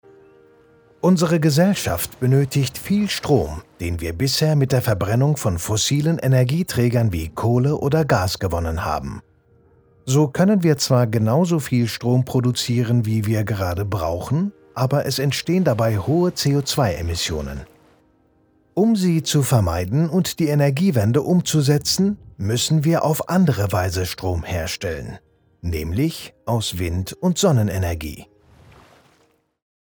Synchronsprecher in 4 Sprachen: Deutsch, Englisch, Türkisch und Arabisch.
Kein Dialekt
Sprechprobe: Industrie (Muttersprache):